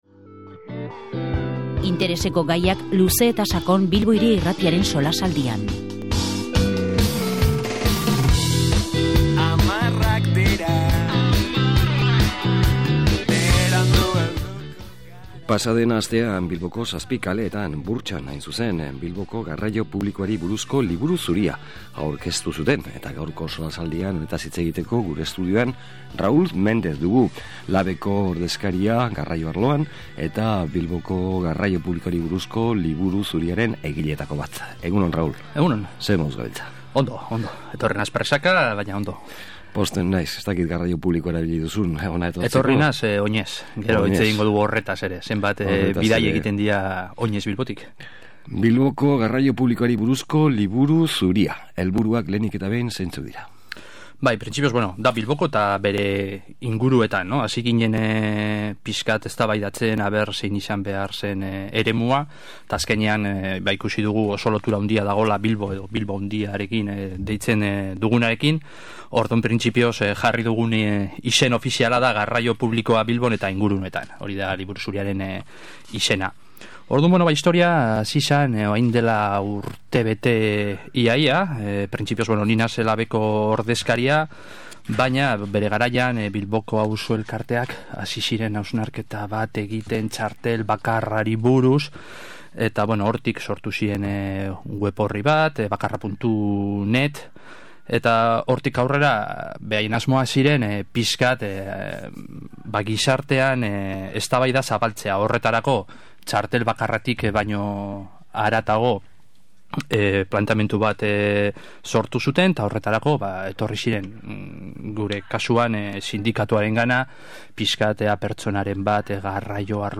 SOLASALDIA: Bilboko garraio publikoari buruzko Liburu Zuria